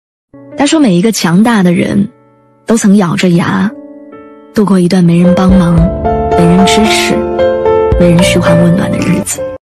236个精选励志人声音频下载